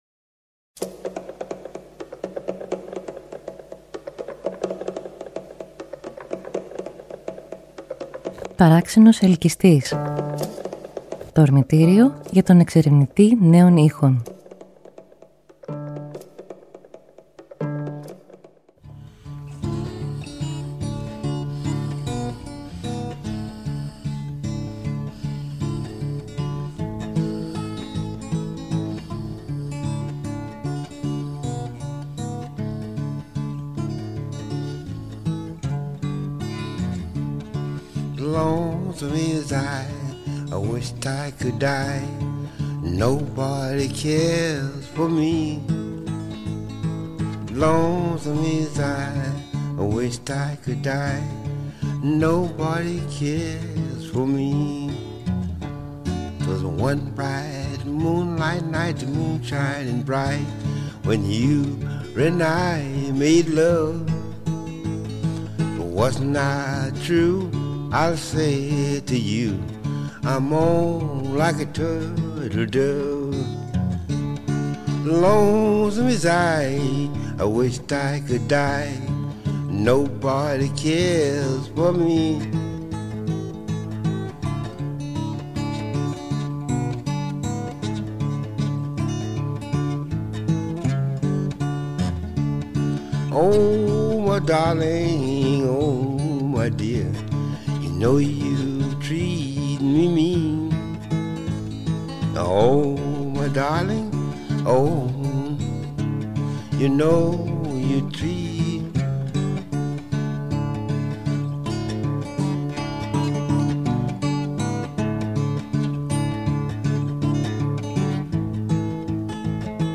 Απόψε ο παρΑξενος_ελκυστΗς κάνει μια φεγγαρόλουστη περιπλάνηση στους ήχους των ιθαγενών της Αμερικής, μέσα από: μπλουζ του Μισισιπή ινδιάνικες φλογέρες της φυλής των Navajo